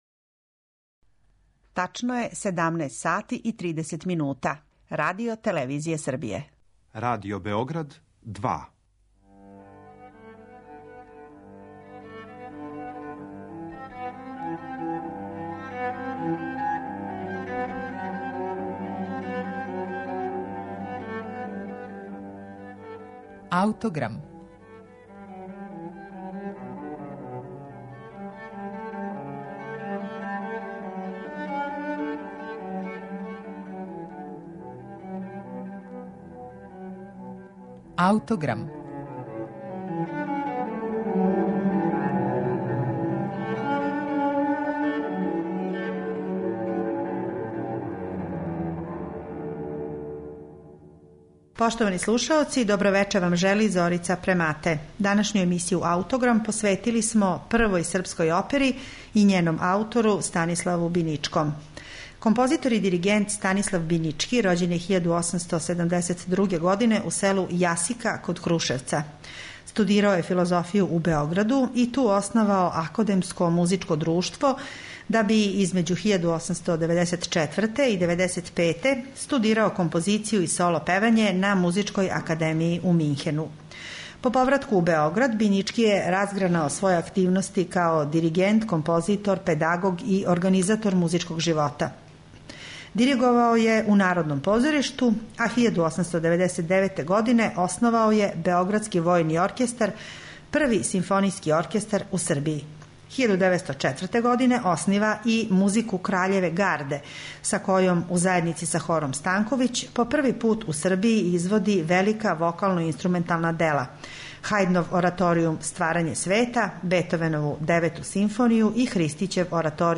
Прва српска опера
коју ћете слушати са нашег архивског снимка начињеног 1968. године.
сопран
мецосопран
тенор